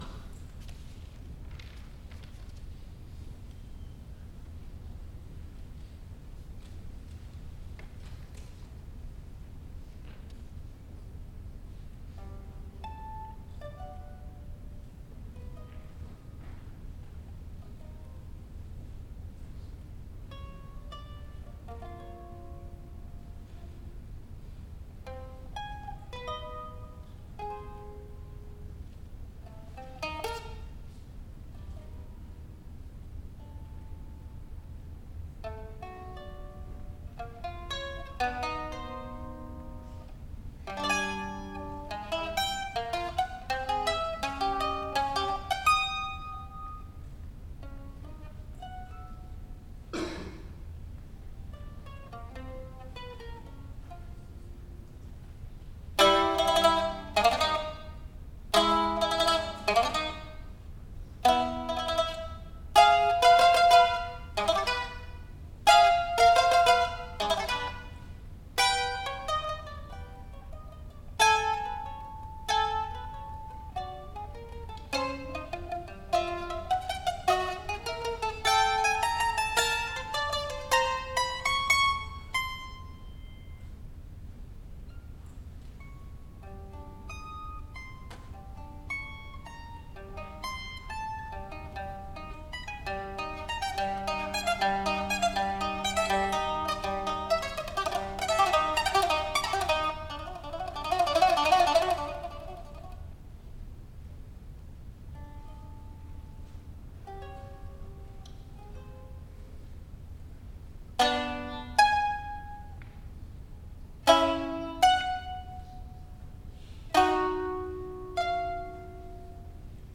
ソロとアンサンブルの夕べ(1980.7.2 府立文化芸術会館)
マンドリン独奏